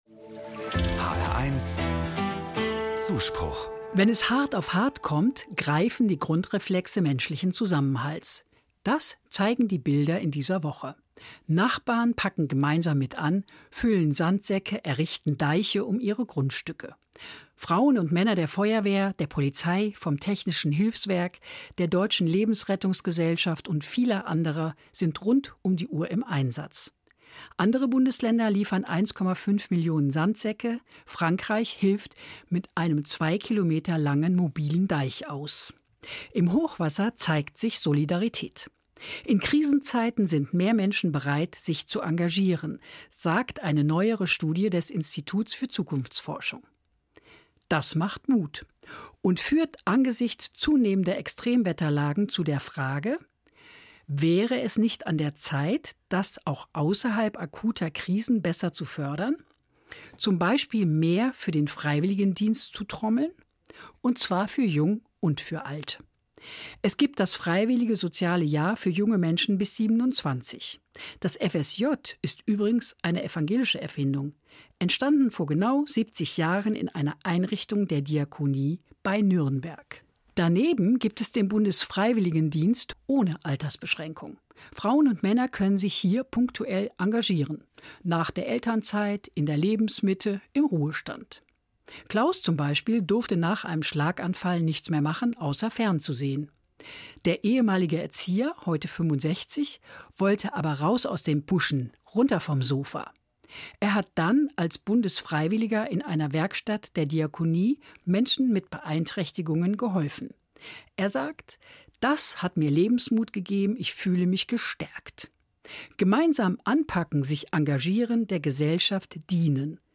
Evangelische Theologin, Oberursel